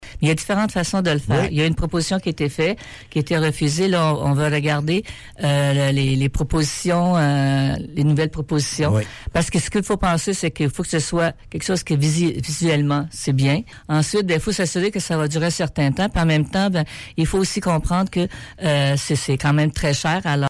Invitée à la chronique Michaud, Mi-Froid, Yolande Hould a aussi expliqué que son conseil étudiera attentivement les méthodes et les dépenses qui seront utilisées pour améliorer la plage et la promenade de la mer.